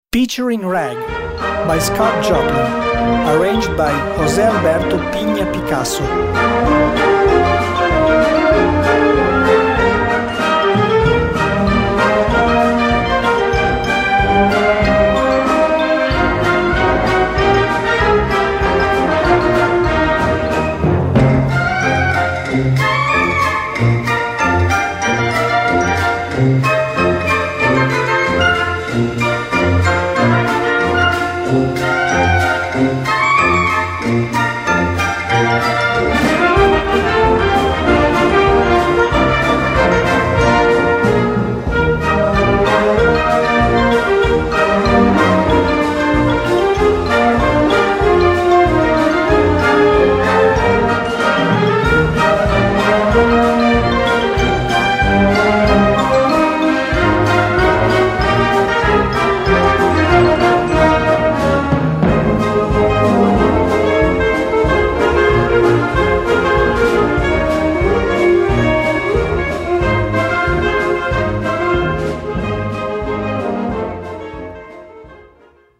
Gattung: Ragtime
Besetzung: Blasorchester